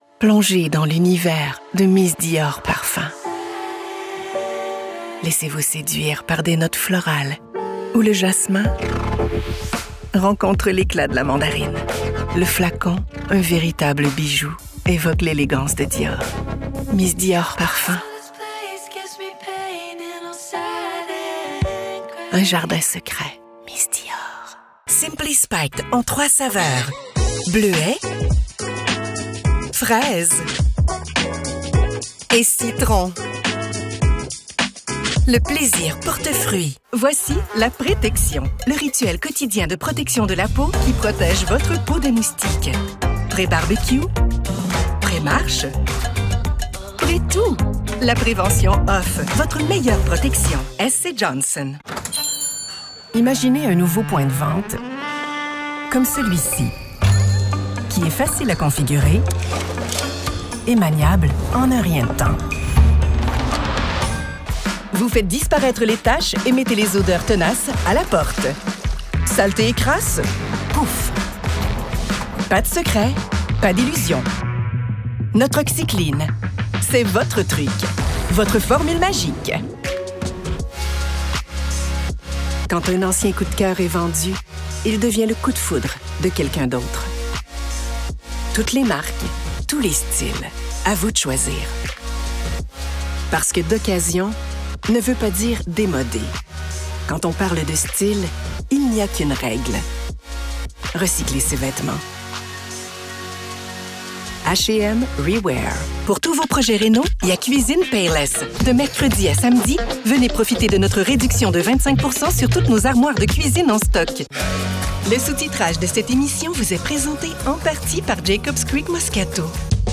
Seasoned and Global French Canadian Voice Artist
Showcase commercial voices
Quebec accent or normative French Canadian
My voice has “oomph”, a unique grain and a funky edge that adds punch; making it catchy, reassuring, sensitive and true all at once.
⭐Professional-grade personal studio